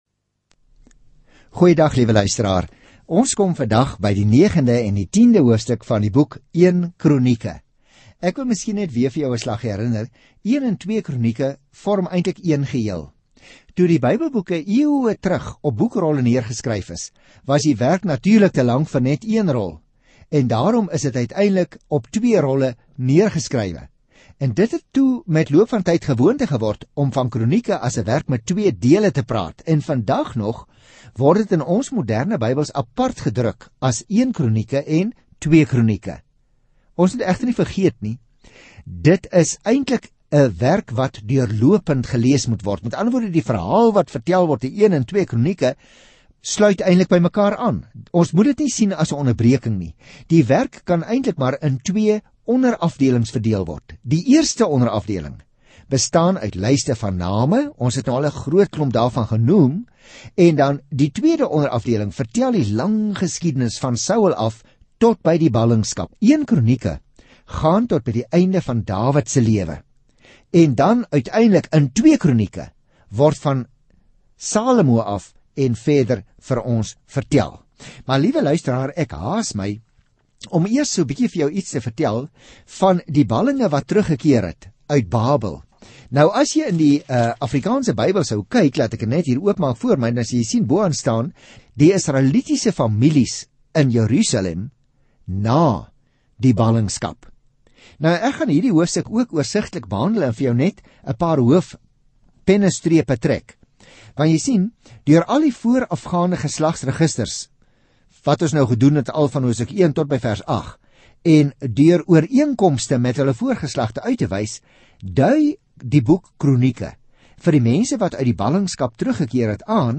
Skrif 1 KRONIEKE 9 1 KRONIEKE 10 Dag 2 Begin met hierdie leesplan Dag 4 Aangaande hierdie leesplan Die Kronieke is geskryf om God se volk wat uit ballingskap teruggekeer het, te herinner hoe groot Hy vir hulle was deur hulle geskiedenis. Reis daagliks deur 1 Kronieke terwyl jy na die oudiostudie luister en uitgesoekte verse uit God se woord lees.